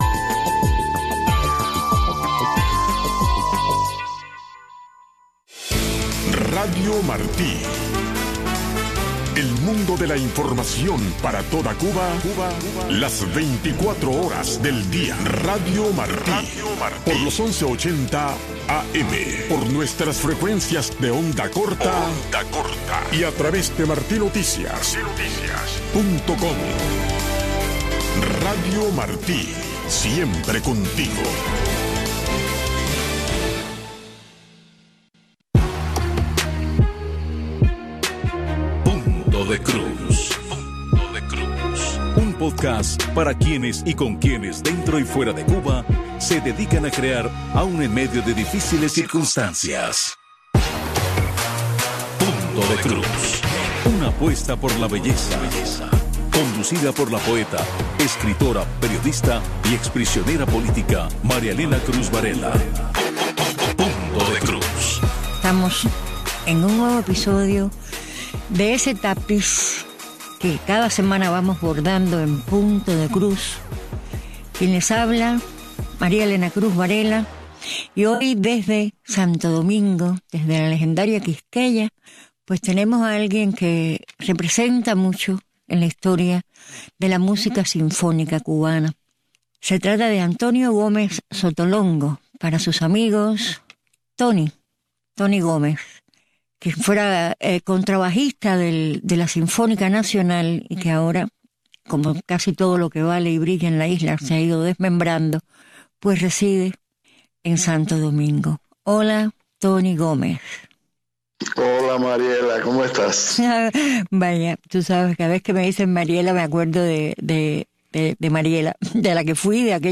Las voces que testimonian la vida del cubano de a pie.